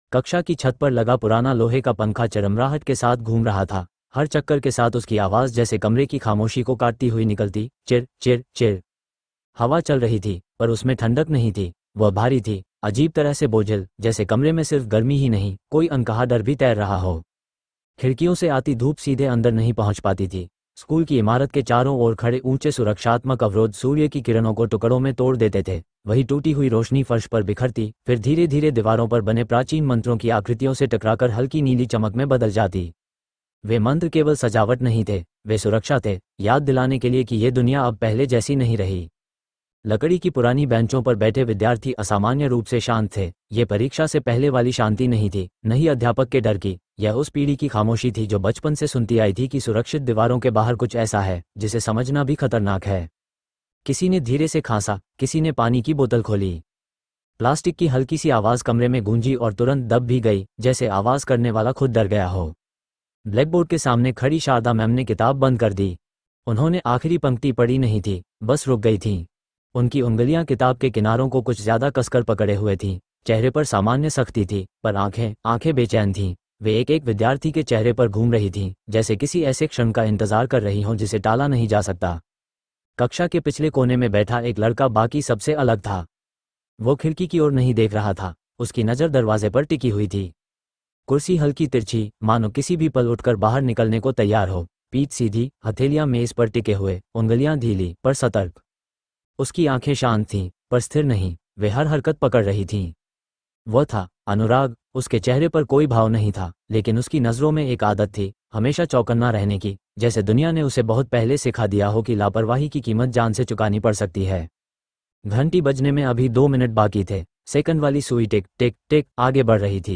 Born in Kalyug is a dark fantasy anime-style audio story filled with reincarnation, supernatural powers, epic battles, and mysterious prophecies.